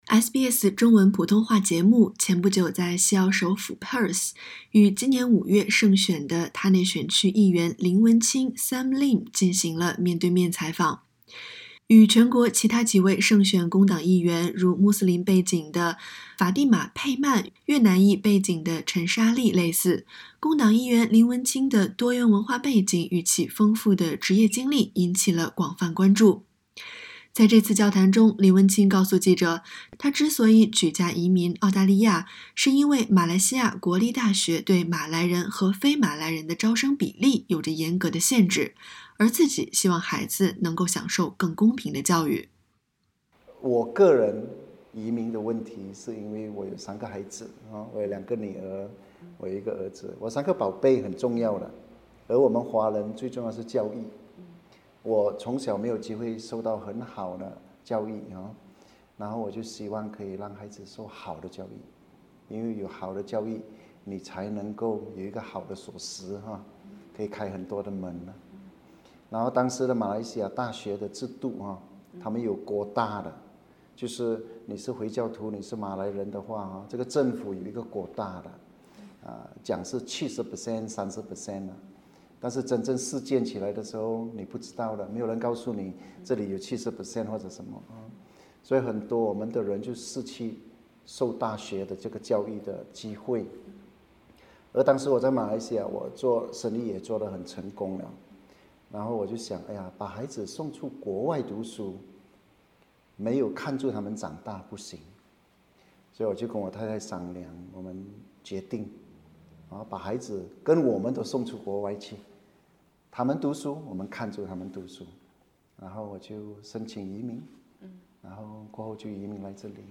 SBS中文普通话节目前不久在珀斯与联邦议员林文清进行了面对面采访。林文清告诉记者，他选择从马来西亚移民澳洲是为了给孩子更公平的教育机会。